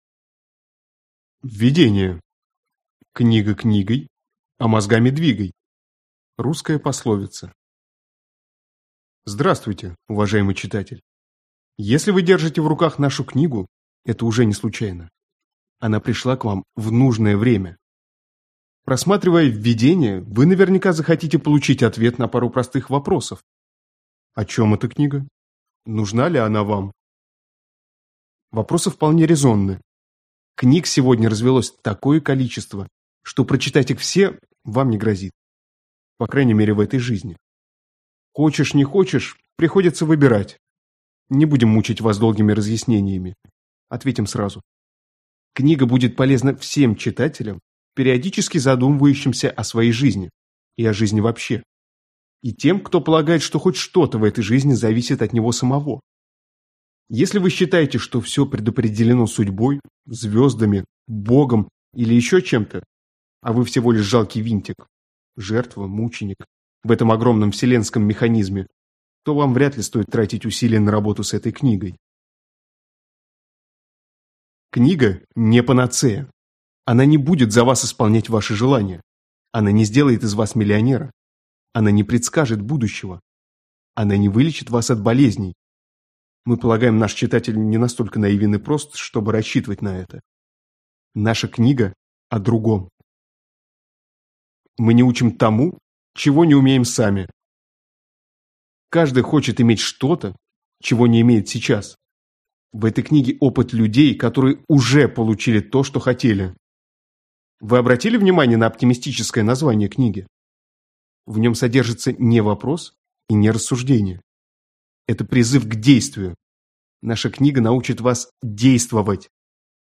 Аудиокнига Улыбнись, пока не поздно. Позитивная психология для повседневной жизни | Библиотека аудиокниг